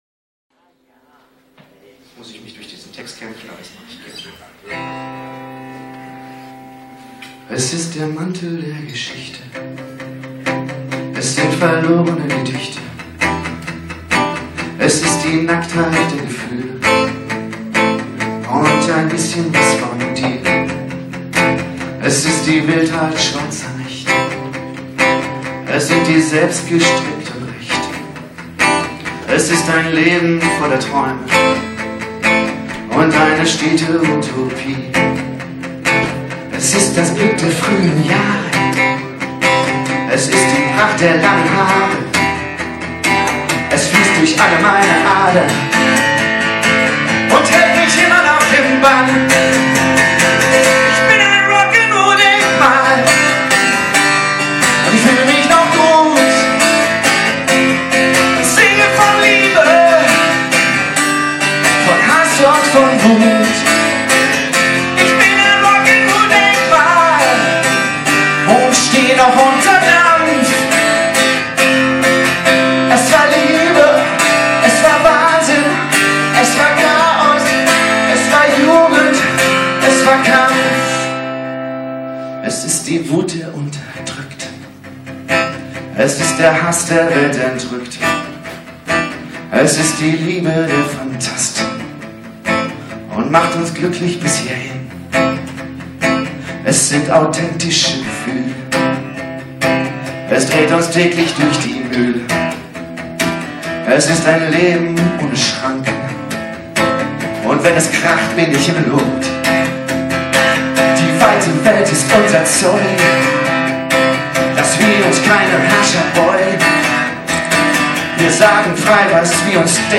diesmal im Brauhaus Guetersloh.
Mono-Tonmitschnitt